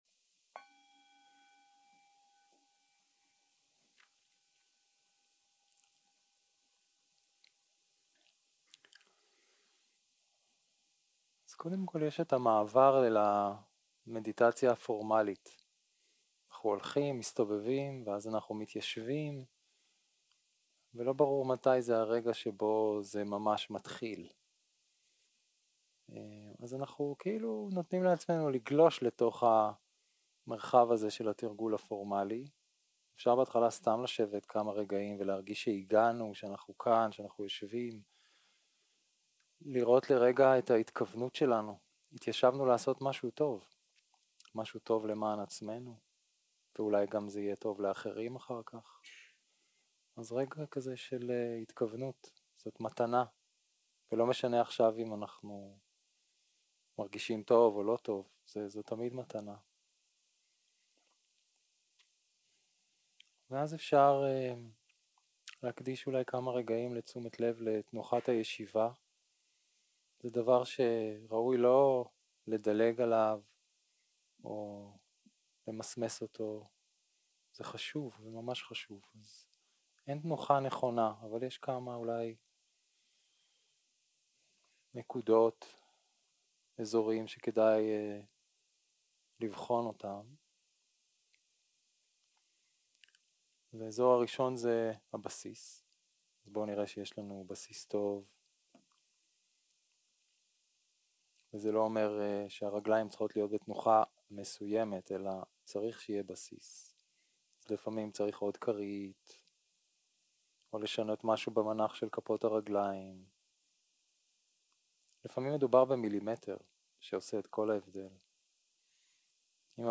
יום 2 - הקלטה 3 - צהרים - מדיטציה מונחית - לנוח עם השאיפה והנשיפה 0:00 0:00 סוג ההקלטה: Dharma type: Guided meditation שפת ההקלטה: Dharma talk language: Hebrew